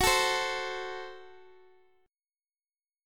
F#dim Chord